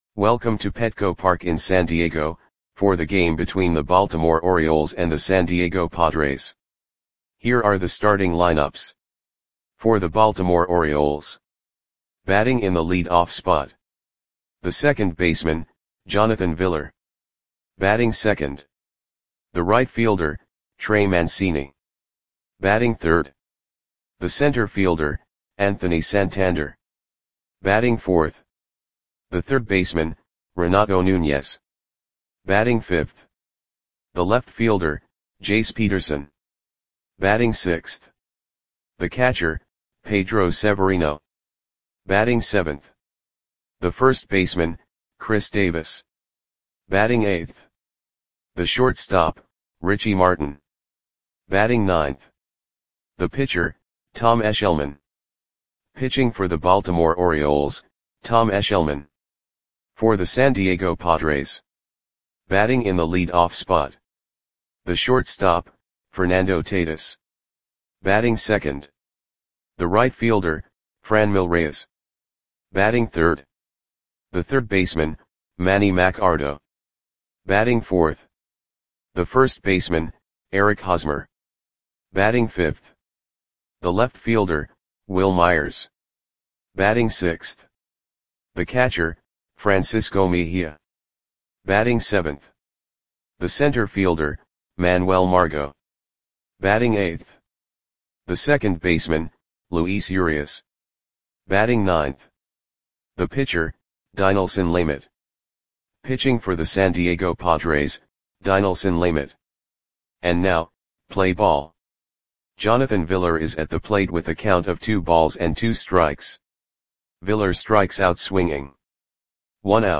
Audio Play-by-Play for San Diego Padres on July 30, 2019
Click the button below to listen to the audio play-by-play.